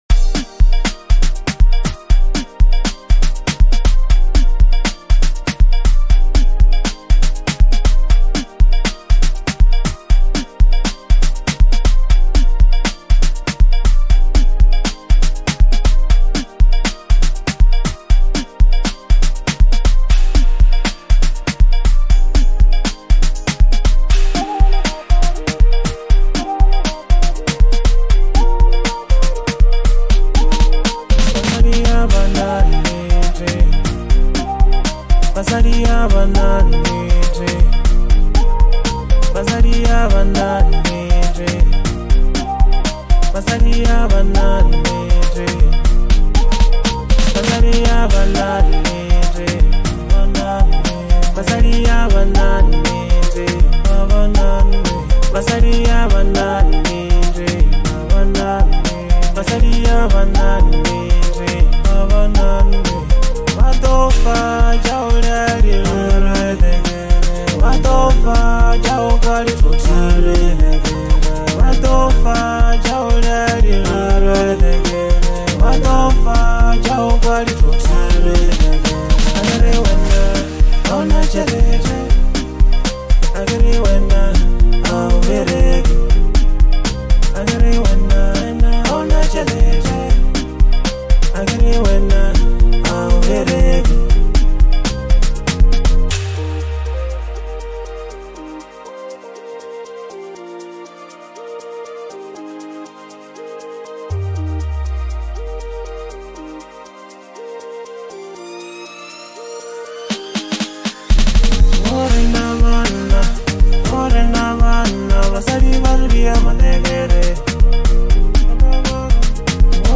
Bolo HouseLekompoMusic